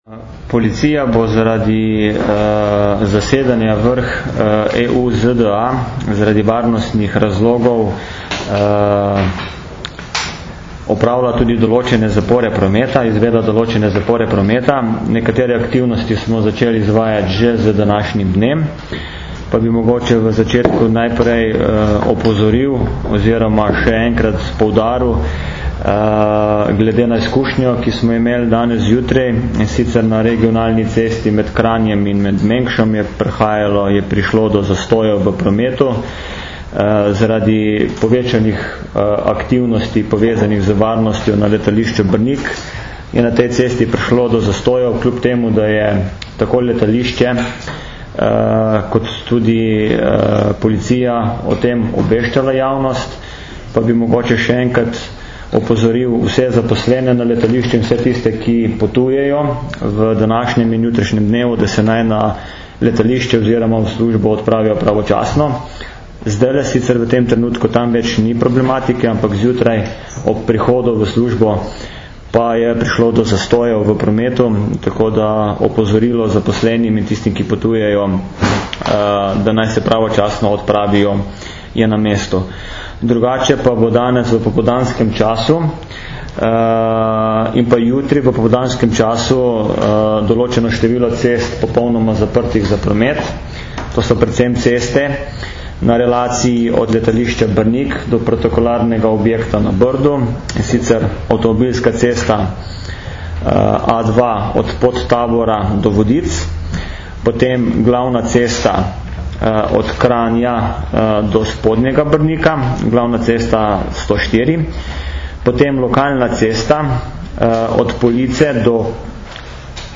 Izjava za javnost o spremenjenem prometnem režimu zaradi Vrha EU - ZDA
Objavljamo zvočni posnetek z današnje izjave za medije v zvezi s prometnimi zaporami zaradi vrha EU - ZDA, ki poteka v teh dneh na Brdu pri Kranju.